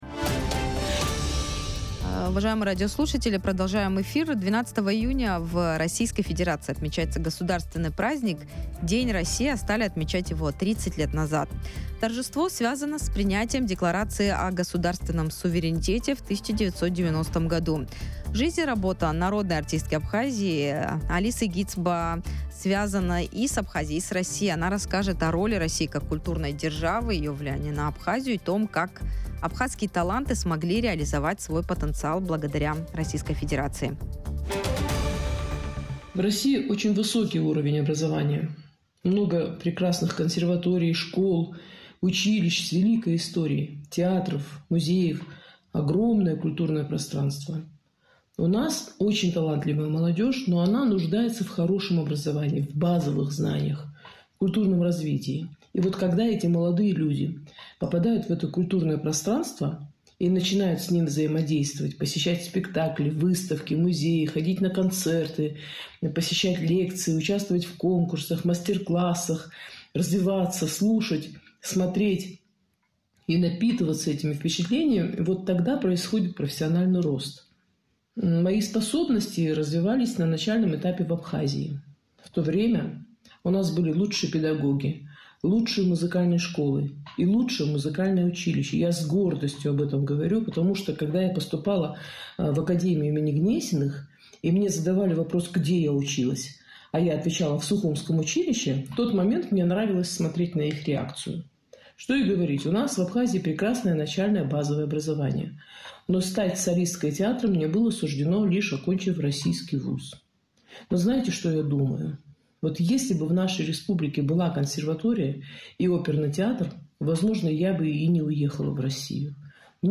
В эфире радио Sputnik Народная артистка Абхазии